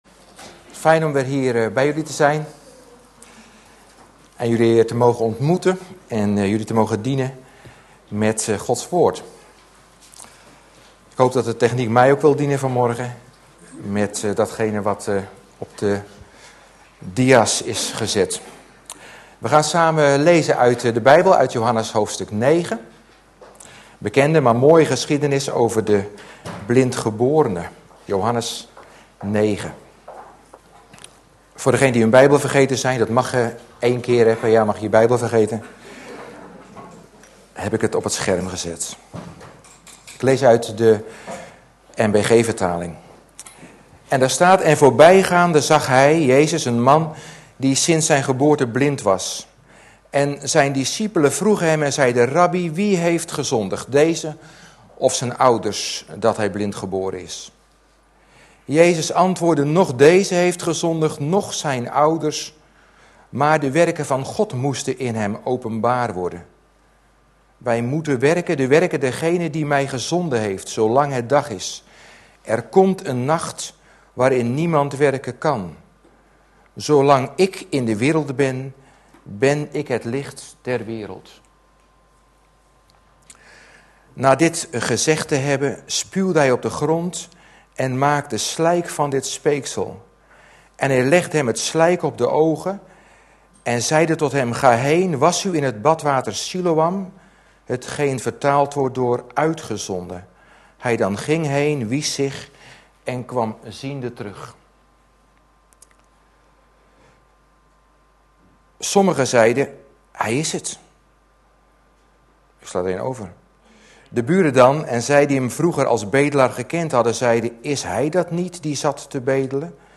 Excuses voor de slechte kwaliteit van deze opname. Download Audiobestand (MP3) Bijbehorende powerpoint Beschrijving Johannes beschrijft zeven wonderen.